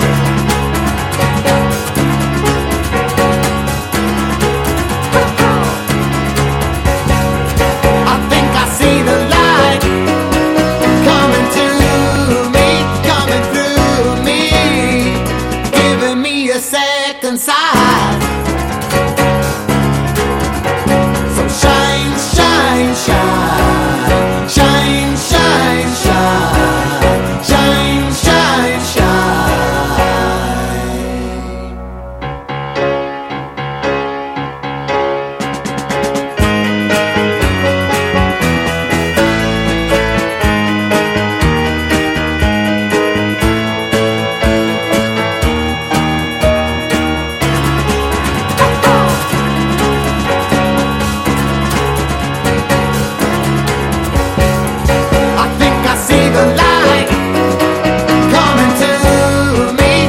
ROCK / S.S.W./A.O.R. / FOLK / SWAMP ROCK
プリA.O.R.～スワンプ好き必聴のフォーキー・デュオ！